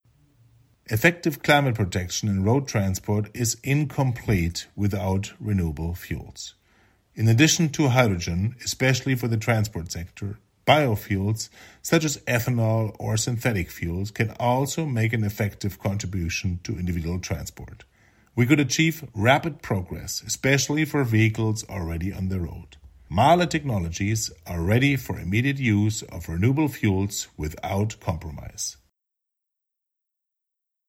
Soundbite